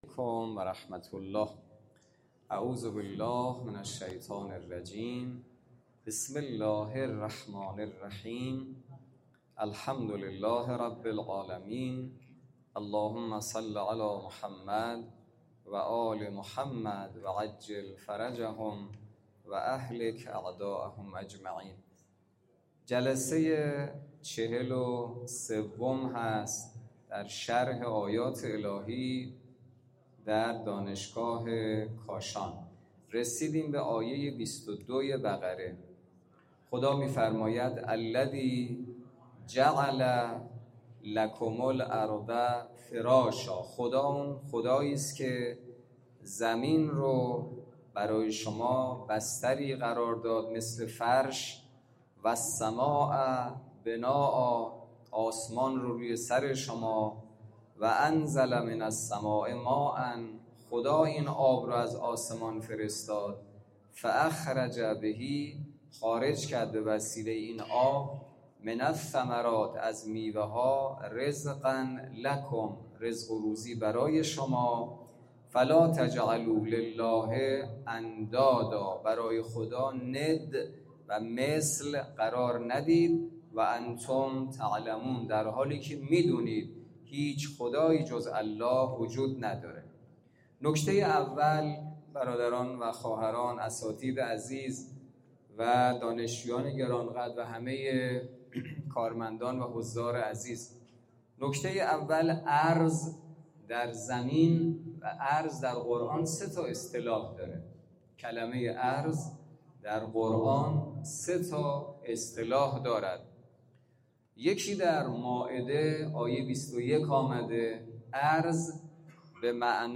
برگزاری بیست و هفتمین جلسه تفسیر سوره مبارکه بقره توسط امام جمعه کاشان در مسجد دانشگاه.
بیست و هفتمین جلسه تفسیر سوره مبارکه بقره توسط حجت‌الاسلام والمسلمین حسینی نماینده محترم ولی فقیه و امام جمعه کاشان در مسجد دانشگاه کاشان برگزار گردید.